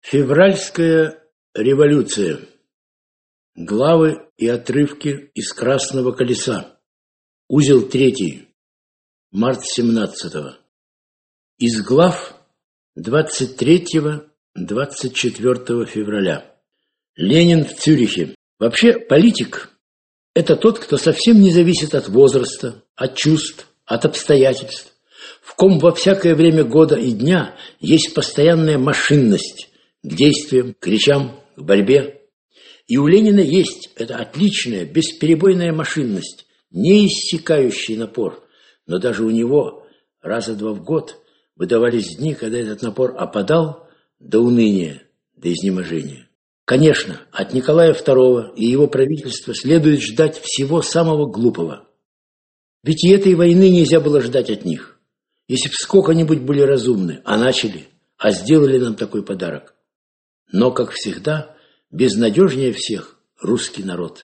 Аудиокнига Красное колесо. Март семнадцатого. Читает автор | Библиотека аудиокниг
Читает автор Автор Александр Солженицын Читает аудиокнигу Александр Солженицын.